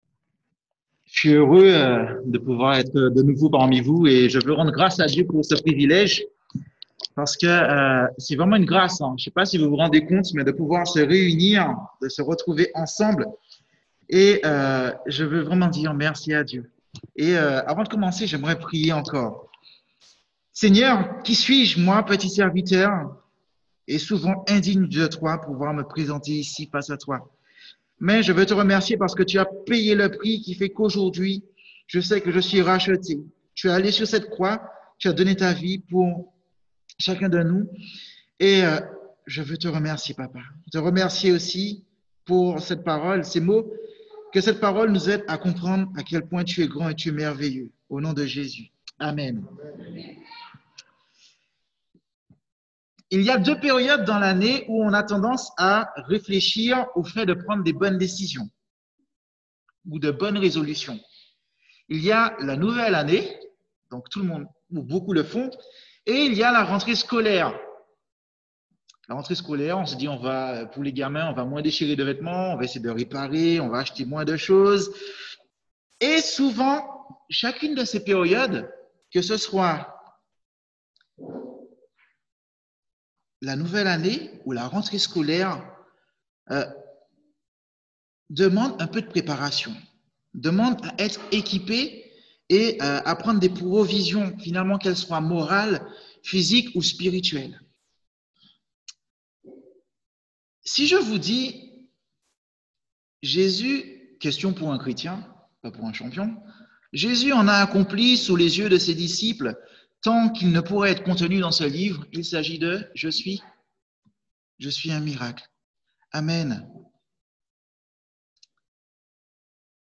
John 2:1-11 Type De Service: Messages du dimanche L'évangile de Jean relate 7 miracles de Jésus.